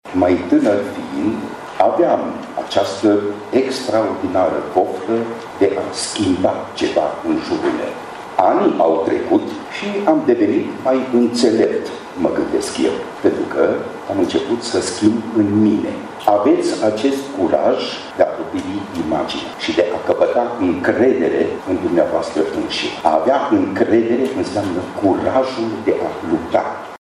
A doua ediție a conferințelor TEDx are loc pe parcursul zilei de astăzi la sala Studio a Universității de Arte și Teatru din Tîrgu-Mureș.